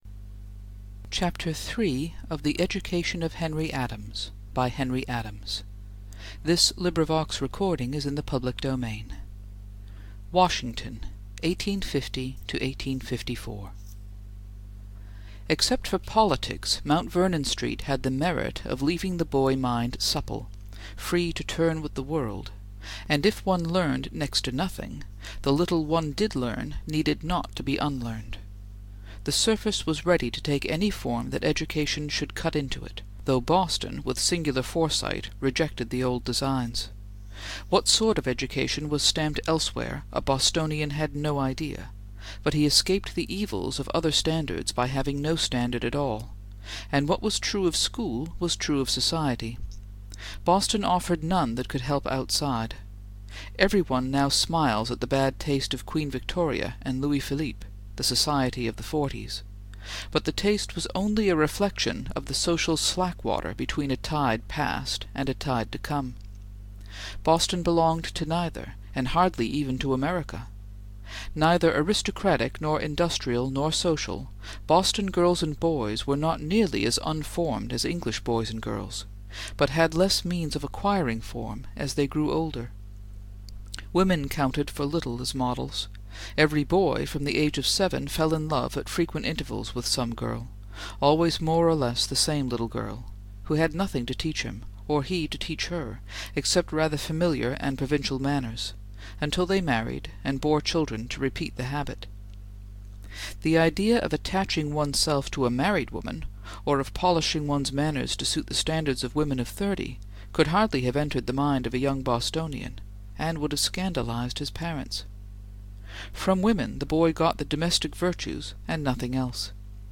English: Chapter 3 of The Education of Henry Adams (1918) by Henry Adams, read aloud for LibriVox.